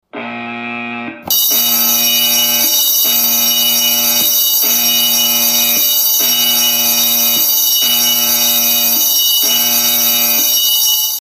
Другие рингтоны по запросу: | Теги: Тревога, сирена
Категория: Различные звуковые реалтоны